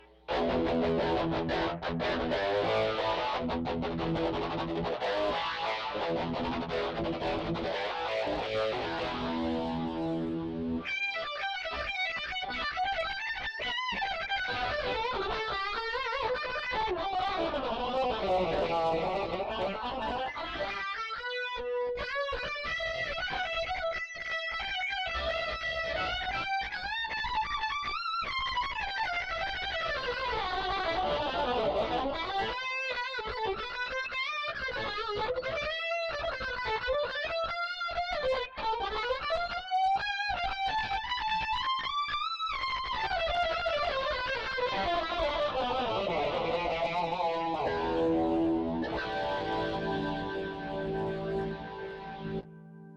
Not great, as I recorded this in Windows 10, and I was playing late at night, but decided that it might help someone.
This is a short demo of the distortion channel on the Rockman Model I Headphone Amp.
It was kinda messy in some parts.
The tone is great, but I have to say, I've never been much into that kind of slow blues playing...kinda puts me to sleep.